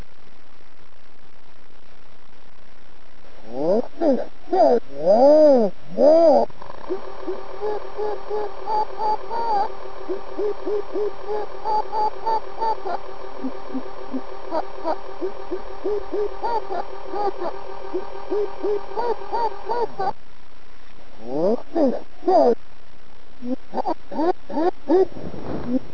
sound sample of original message
sound sample backmasked
Download the wav file of the Elmo Dolls voice with its speed reduced by 100% and played backwards!